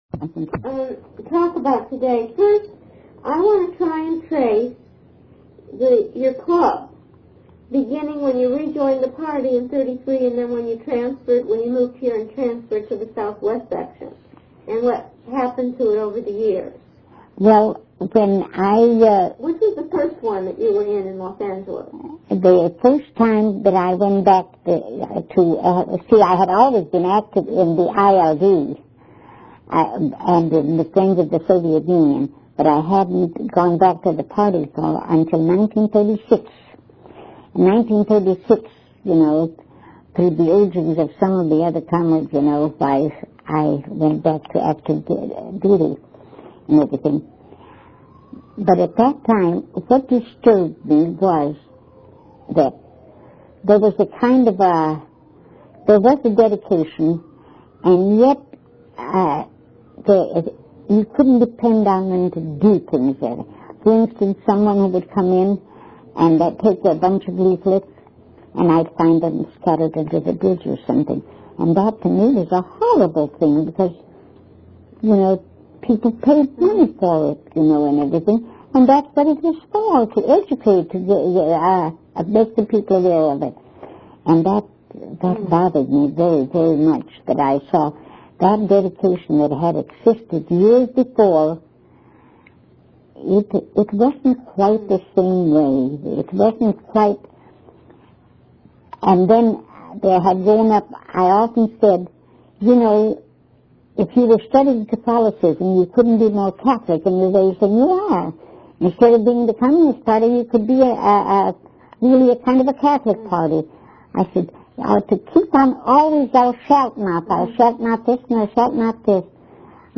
INTERVIEW DESCRIPTION
The audio quality of the interview is fair, although there are a few areas where the recording includes a series of skips.